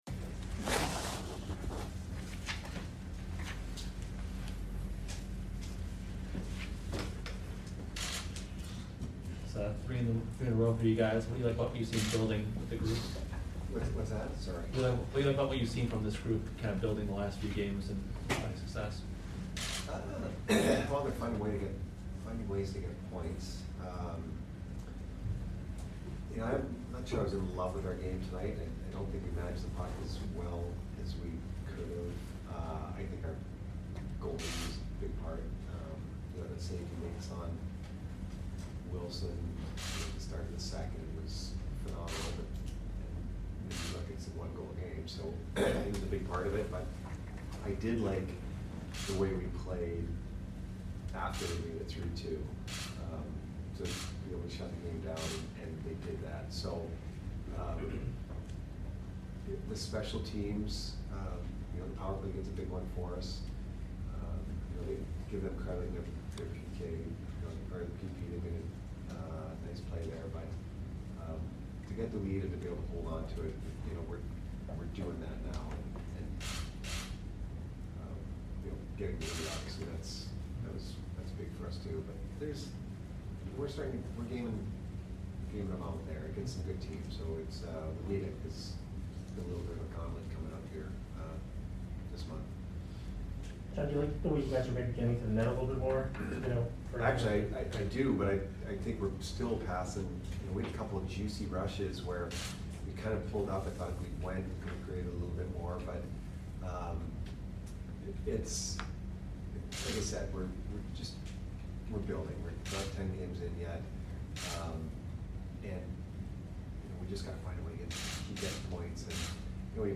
Head Coach Jon Cooper Post Game Vs WAS 11/1/21